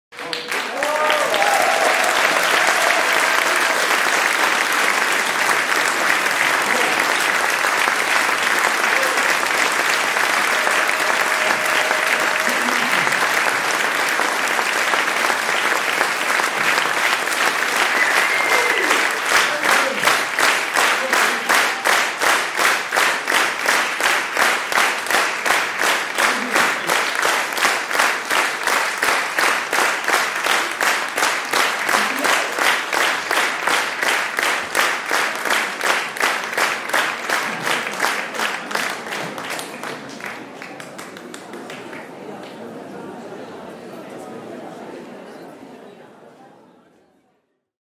Sound Effect Audience crowd sound effects free download
Sound Effect - Audience crowd applause - 09